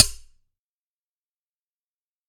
sword_B
field-recording impact metal metallic ping sword sound effect free sound royalty free Sound Effects